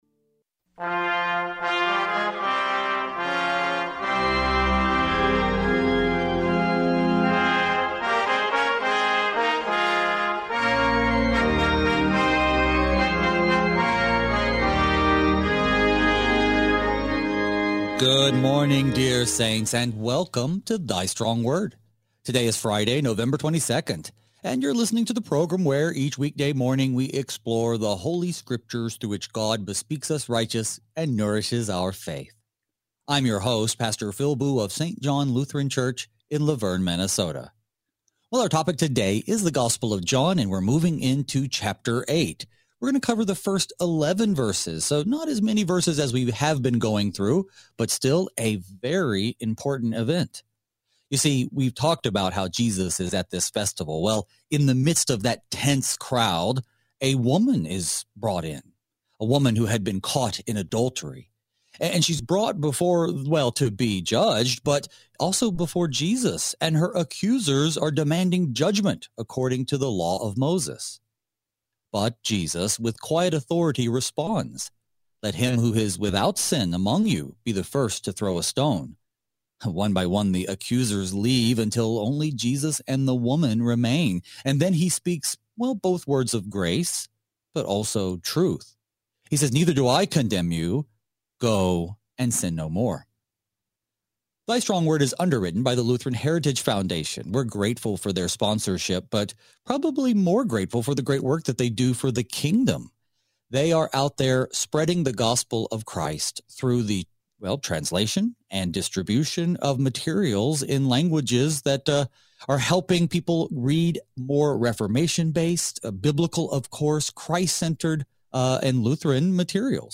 Thy Strong Word reveals the light of our salvation in Christ through study of God’s Word, breaking our darkness with His redeeming light. Each weekday, two pastors fix our eyes on Jesus by considering Holy Scripture, verse by verse, in order to be strengthened in the Word and be equipped to faithfully serve in our daily vocations.